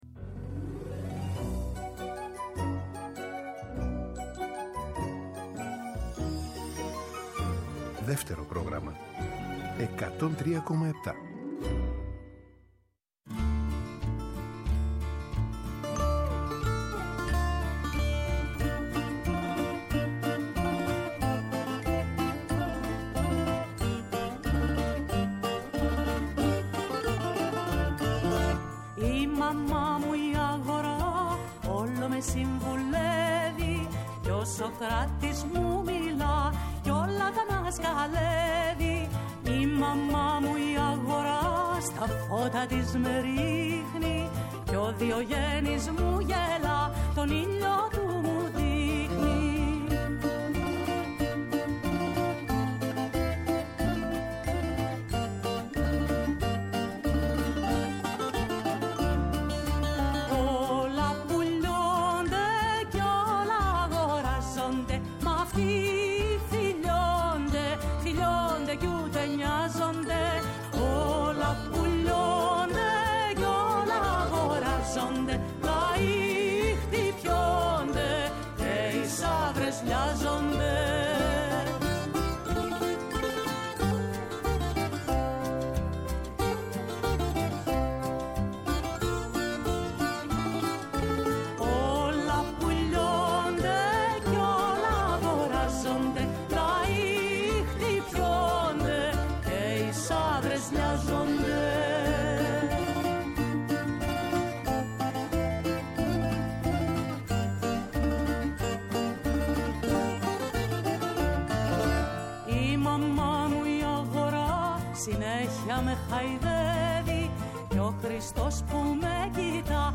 «Έχει η ζωή γυρίσματα» Μία δίωρη ραδιοφωνική περιπλάνηση, τα πρωινά του Σαββατοκύριακου.
Συνεντεύξεις